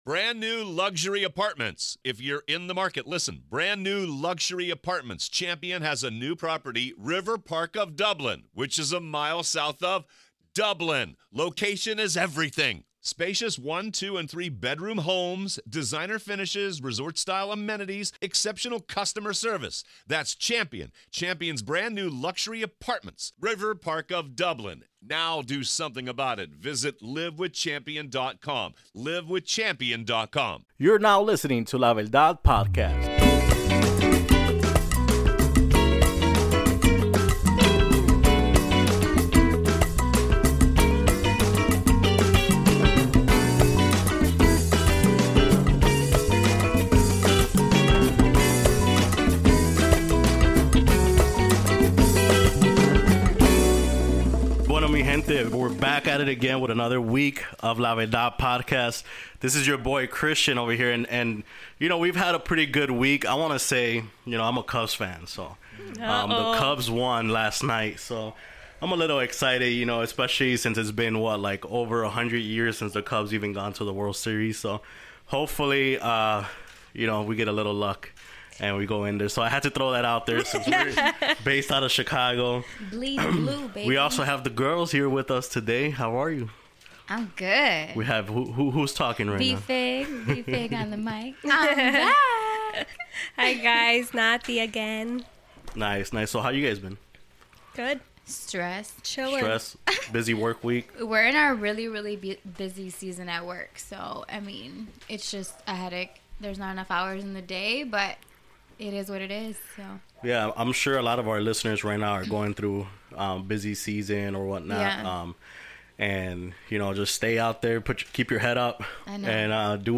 This episode is a special one with a well rounded conversation.